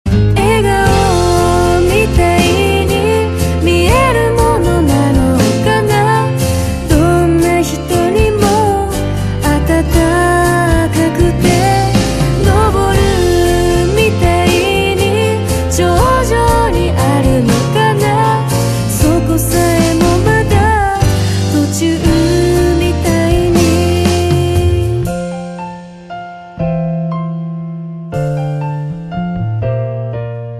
M4R铃声, MP3铃声, 日韩歌曲 108 首发日期：2018-05-15 12:24 星期二